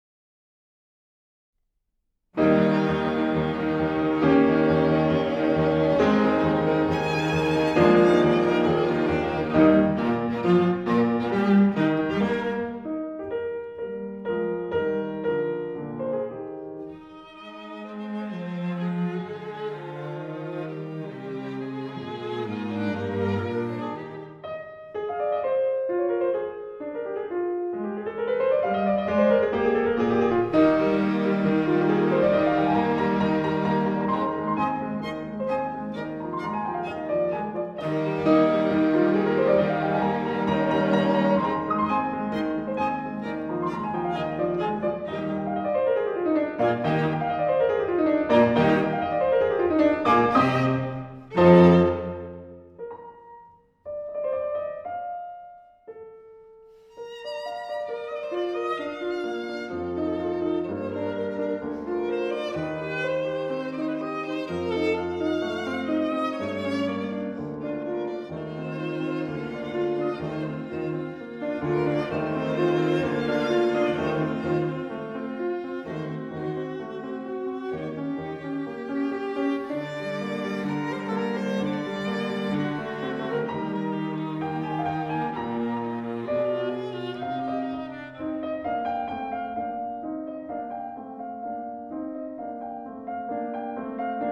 1. Satz: Allegro
movendo_mozart.mp3